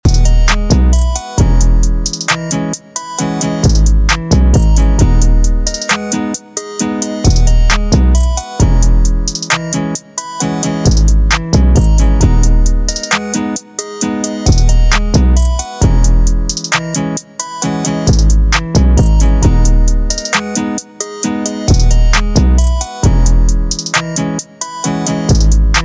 спокойные
без слов
басы
качающие
beats
классный бит